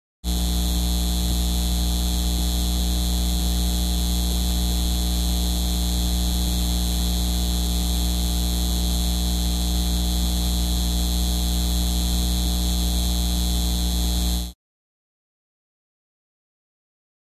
Electrical Buzz From Neon Light Transformer. Close Perspective.